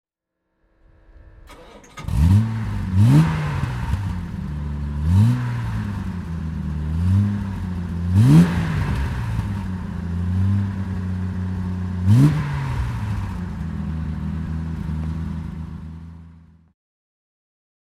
Jaguar E-Type Series 1 3.8 Roadster (1961) - Starten und Leerlauf
Jaguar_E-Type_1961.mp3